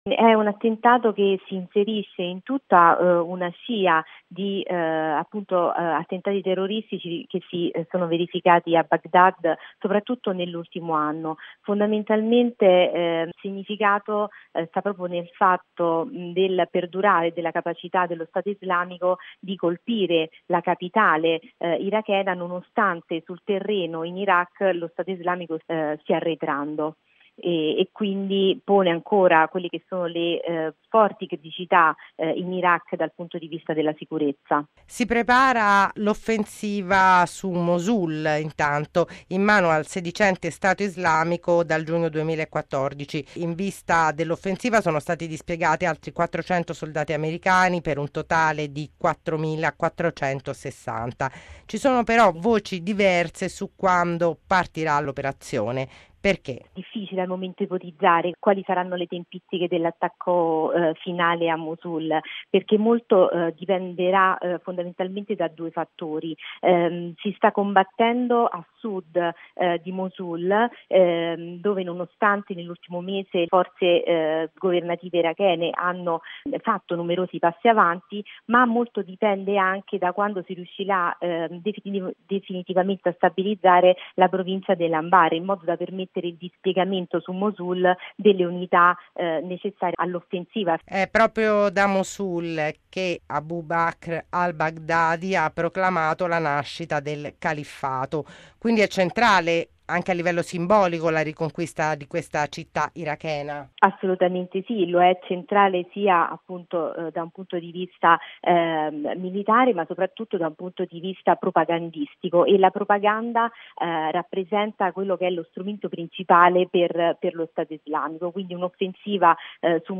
Bollettino Radiogiornale del 10/09/2016